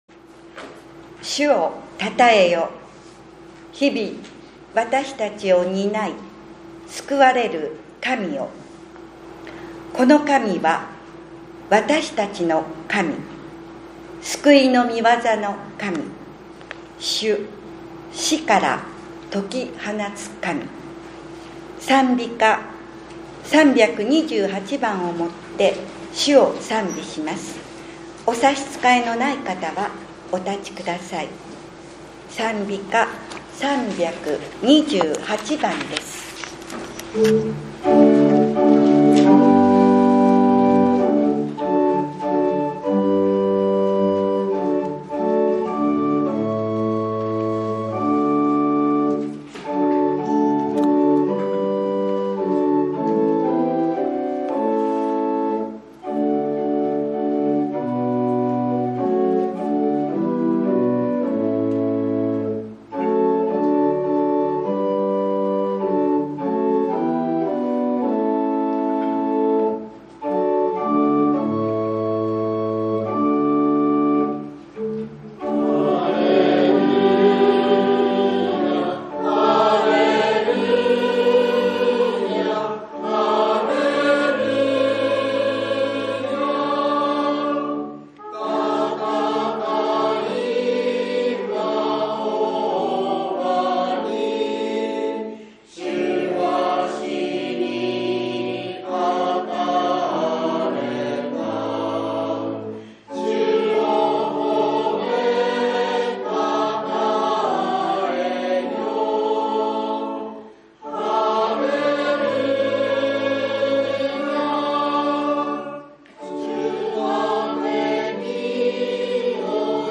４月２７日（日）主日礼拝